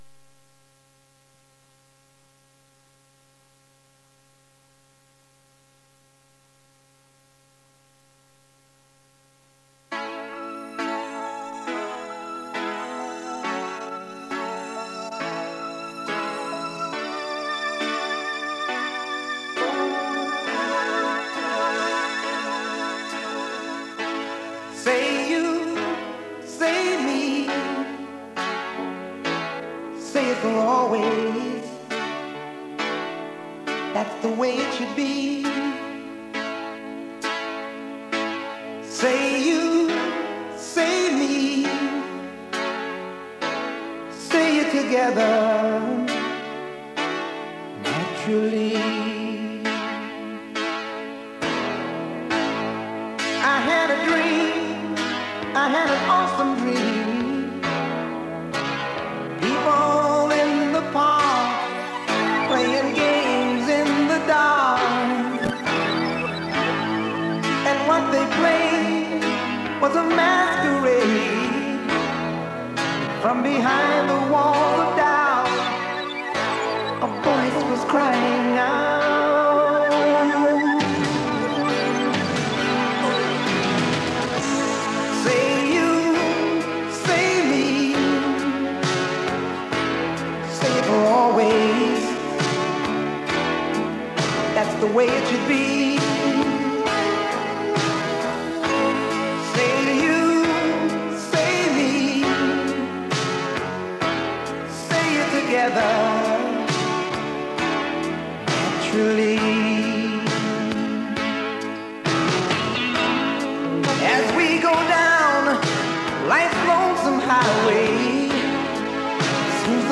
磁带数字化：2022-07-15